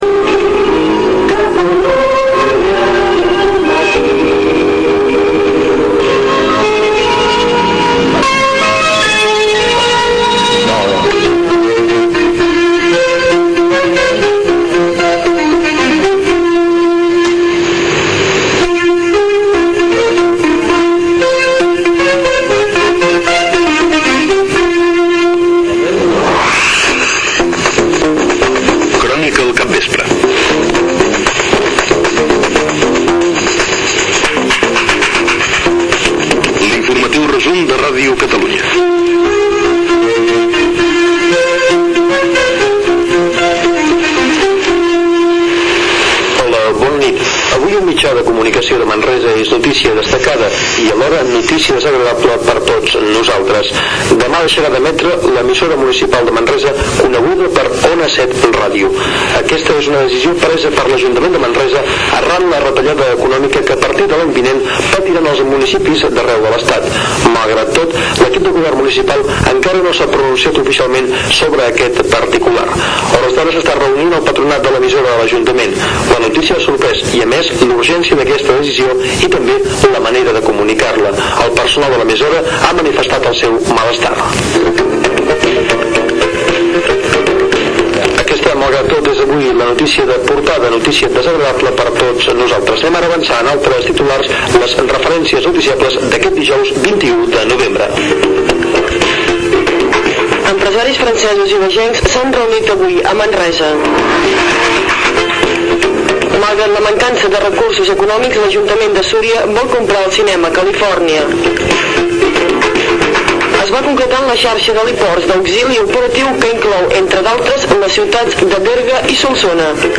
Indicatiu de l'emissora, titulars, indicatiu del programa, tancament d'Ona 7 Ràdio amb un repàs a tota la seva història i orígens, indicatiu del programa.
Informatiu